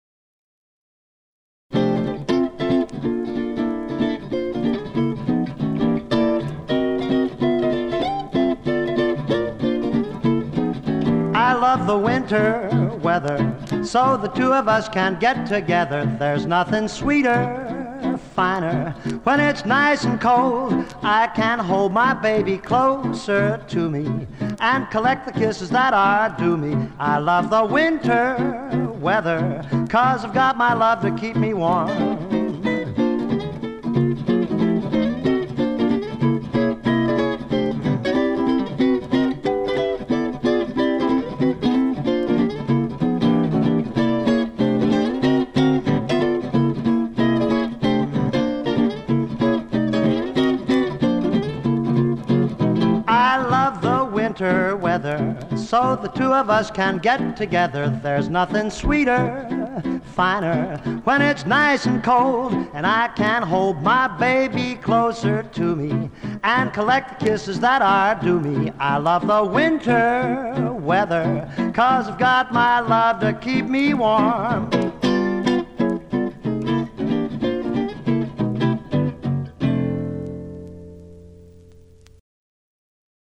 playing and singing